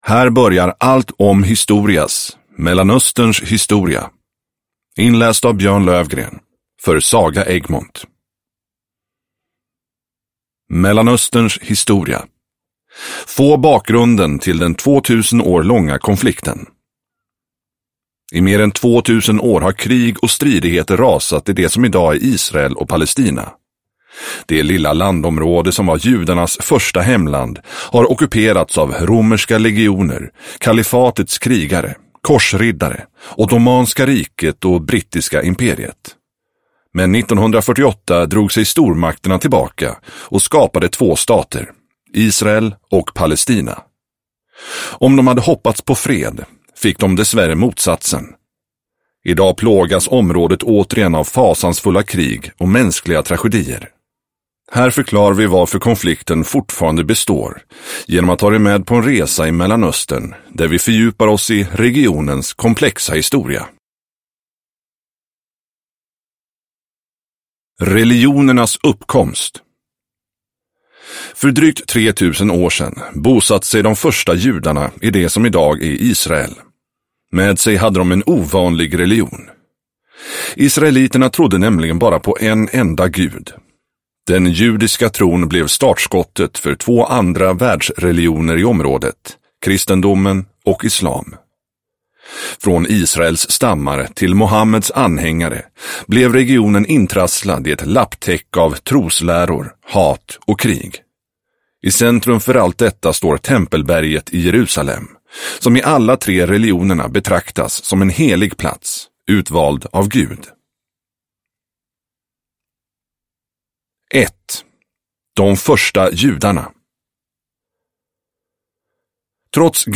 Mellanösterns historia – Ljudbok